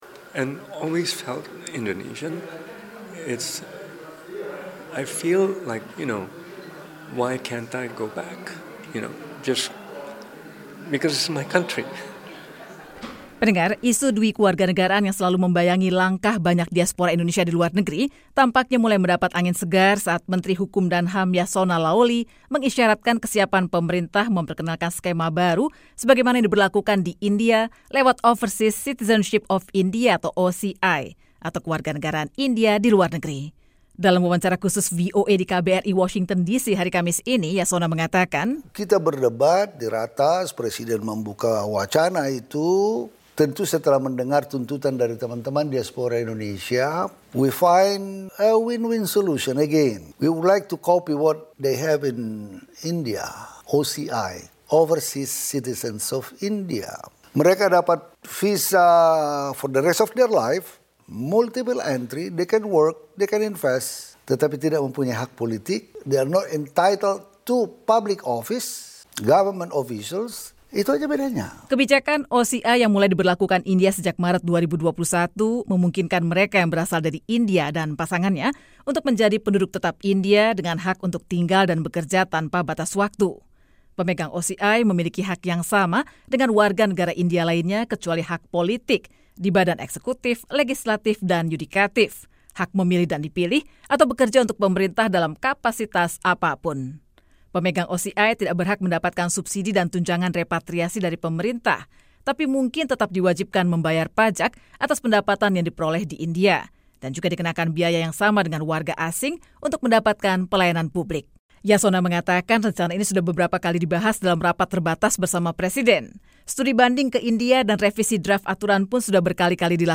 Dalam wawancara khusus VOA di KBRI Washington DC hari Kamis (30/5), Yasonna mengatakan “we would to copy what they have in India – OCI or Overseas Citizens of India. Mereka dapat visa for the rest of their life, multiple entries, they can work, they can invest, tetapi tidak mempunyai hak politik, mereka tidak entitled to public office or being government officials.”